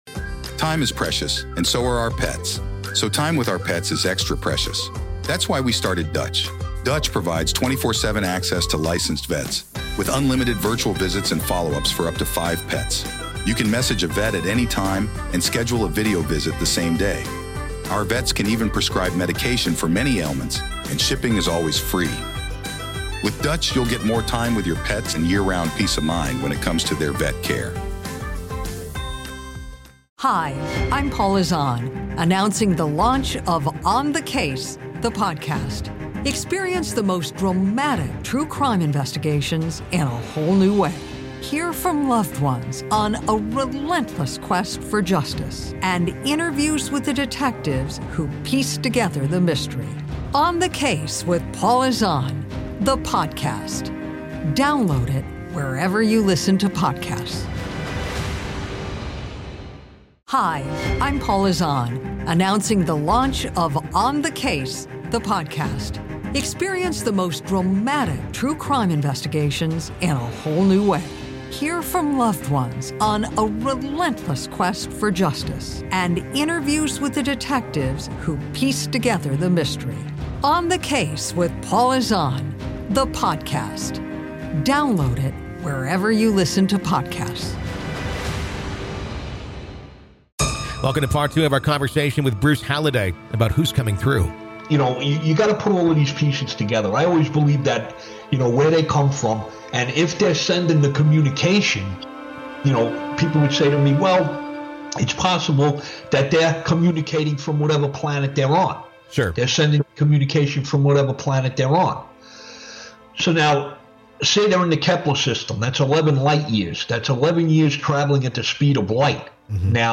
In this gripping conversation, we dive deep into the mystery of Ghost Box communication, exploring theories, shocking encounters, and the terrifying implications of who—or what—might be answering back. This is Part Two of our conversation.